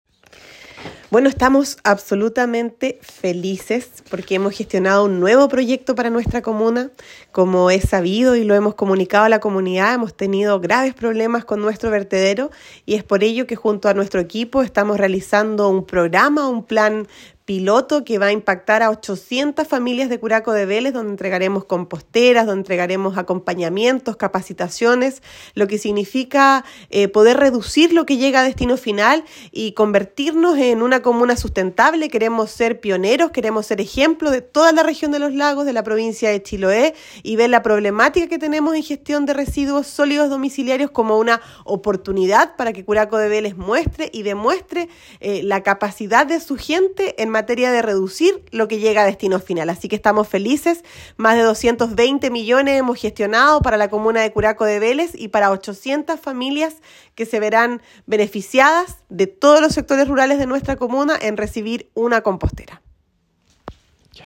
Con estos recursos se contempla la adquisición de 800 composteras de 300 litros para ser entregadas a igual número de familias, capacitación y asistencia por 12 meses, para aportar en la separación y tratamiento en origen, separación de residuos orgánicos de los inorgánicos, para la elaboración de compost, evitando de esta forma, que estos residuos sean dispuestos en el vertedero municipal, adelantó la alcaldesa Javiera Yáñez Rebolledo:
alcaldesa-Javier-Yanez-proyecto.m4a